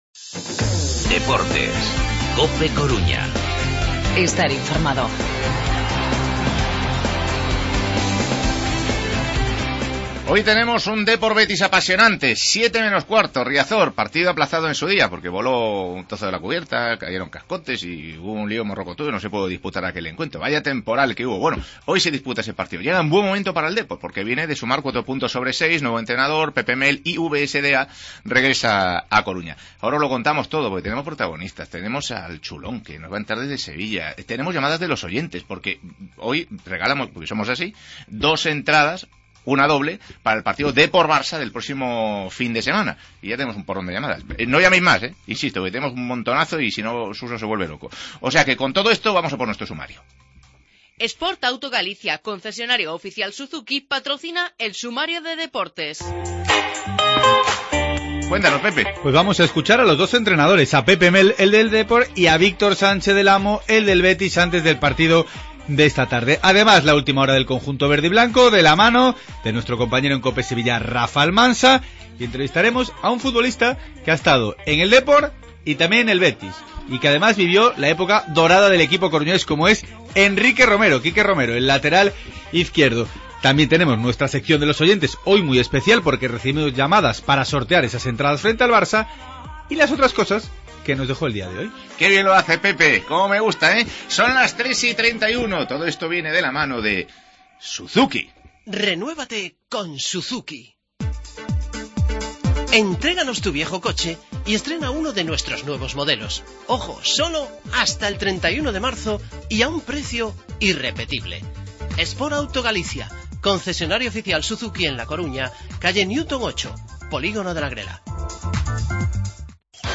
Última hora del Betis-Depor y entrevista